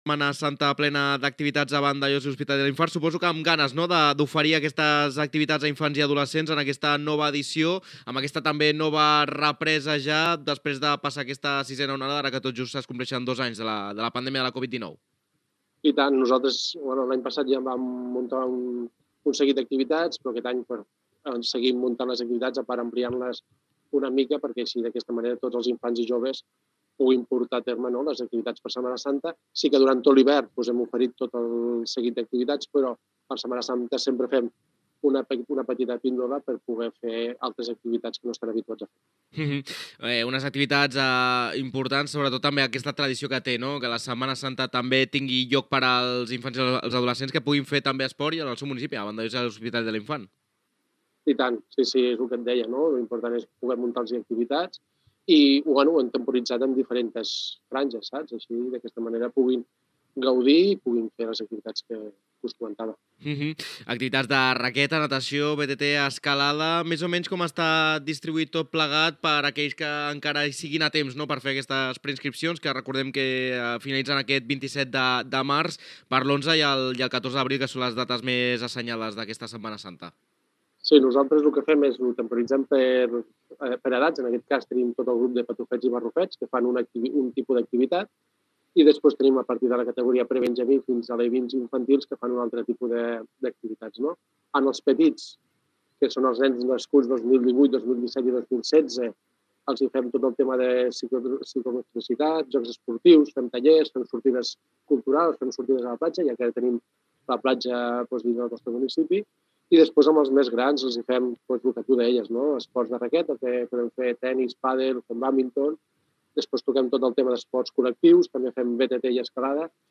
Entrevista
Informatiu